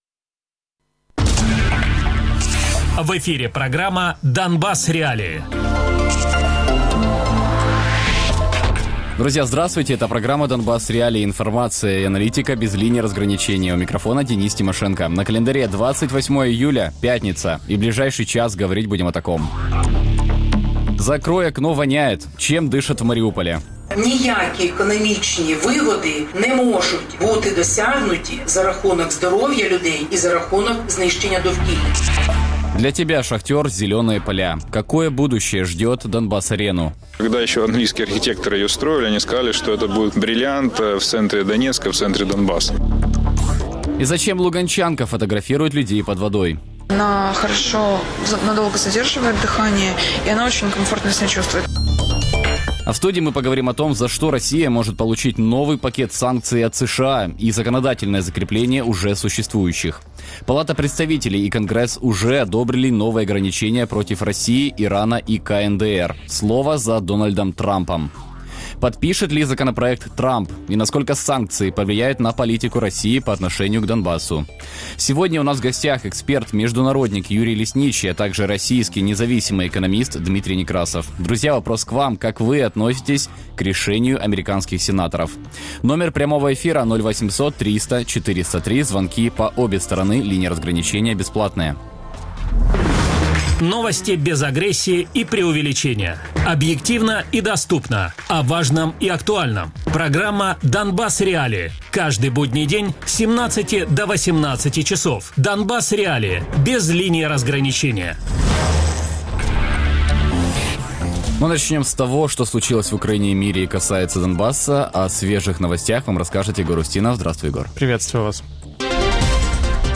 российский независимый экономист Радіопрограма «Донбас.Реалії» - у будні з 17:00 до 18:00.